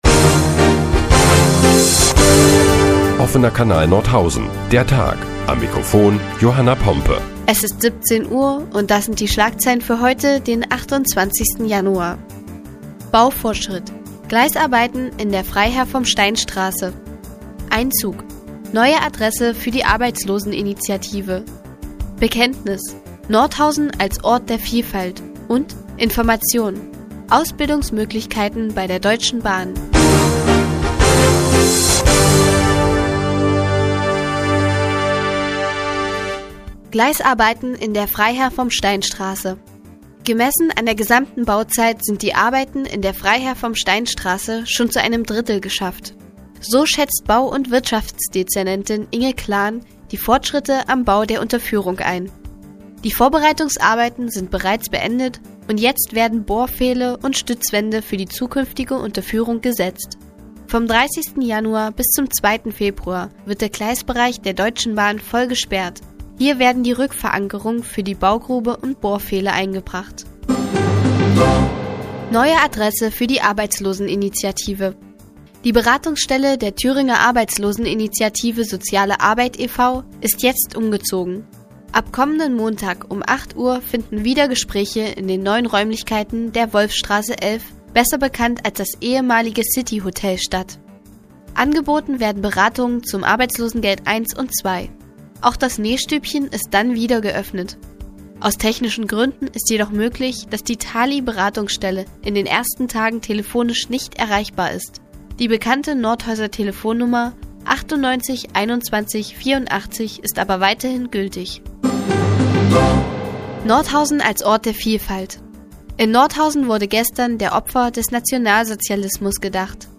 Die tägliche Nachrichtensendung des OKN ist nun auch in der nnz zu hören. Heute geht es unter anderem um Gleisarbeiten in der Freiherr vom Stein Straße und eine neue Adresse für die Arbeitsloseninitiative.